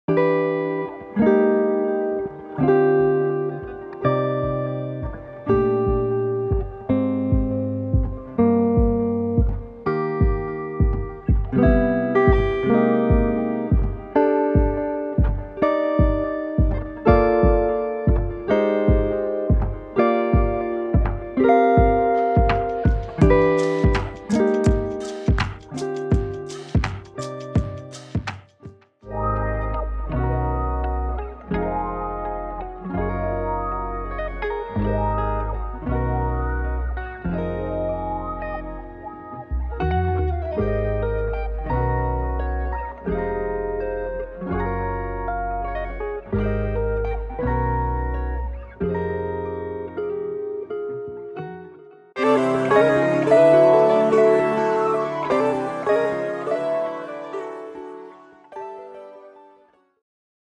guitar and MIDI melodies and chord progressions
delicious melodies, tasty basslines, and ear-catching chords